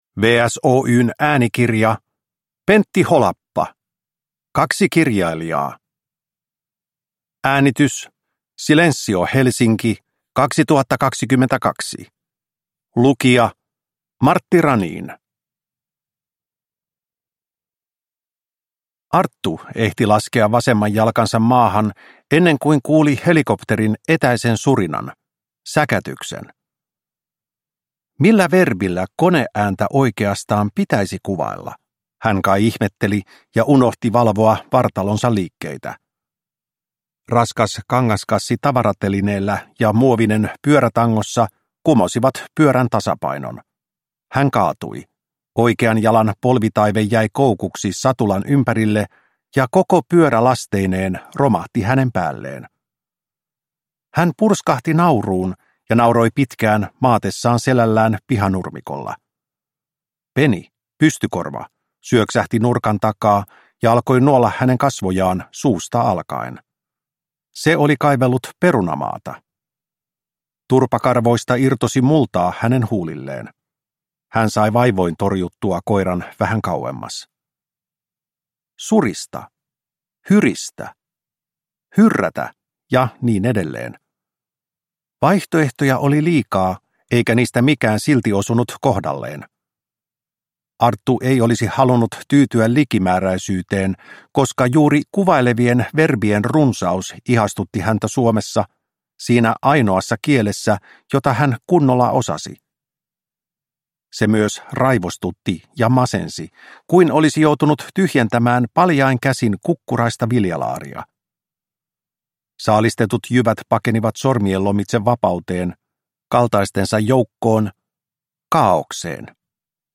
Kaksi kirjailijaa – Ljudbok – Laddas ner